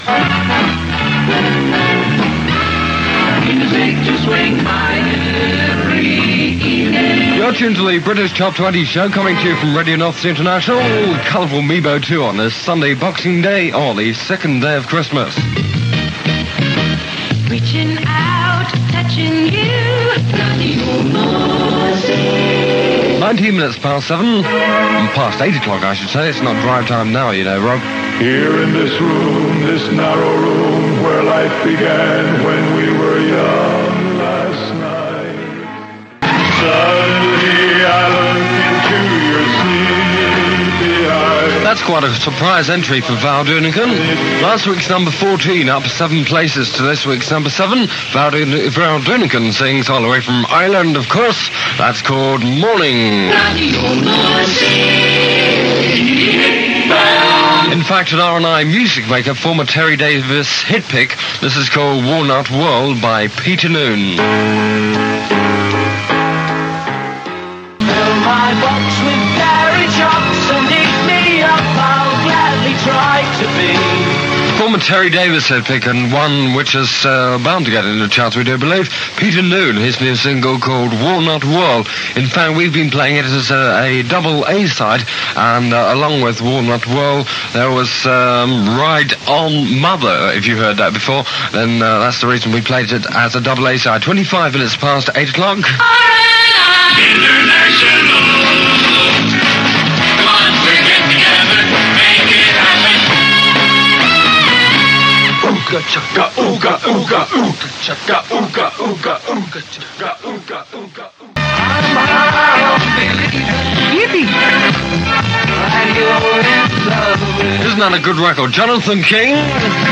from the second hour of the British Top 20 show on Radio Northsea International, 26th December 1971.